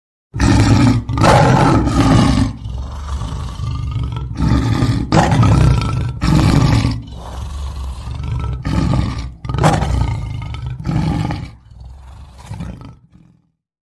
Play Leão Rugindo Trovo - SoundBoardGuy
Play, download and share Leão Rugindo Trovo original sound button!!!!
leao-rugindo-trovo.mp3